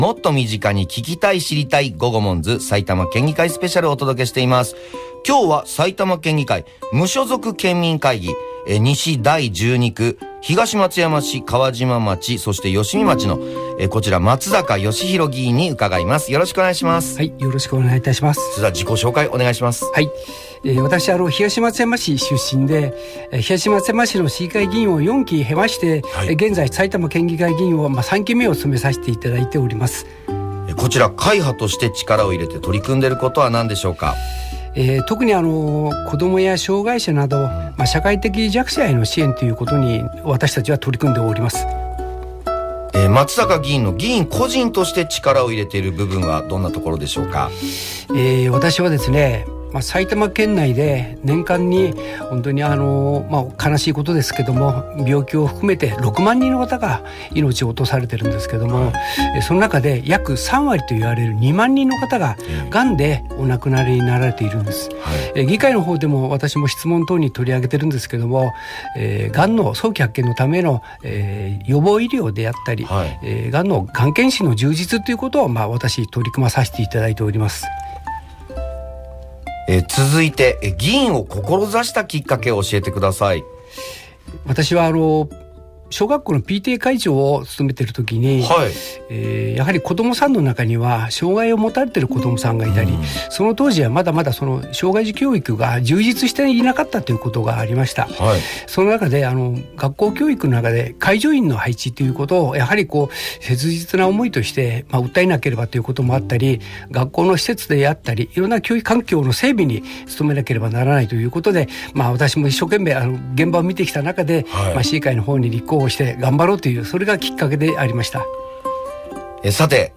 県民の皆さまに県議会や議員を身近に感じていただき、県議会への関心を高めていただくため、埼玉県議会の特集コーナーをFM NACK5の人気番組「GOGOMONZ」内で令和6年11月に放送しました。
10月28日（月曜日）と11月6日（水曜日）にFM NACK5のスタジオにてラジオ収録が行われました。
楽しい話と笑顔が多い現場となりました。